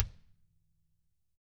DW HARD PD-L.wav